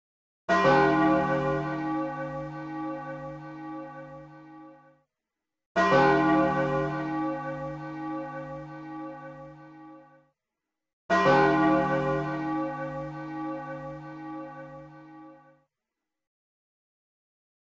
Zelda_Majora's_mask_-_Clock_tower_bell_sound.mp3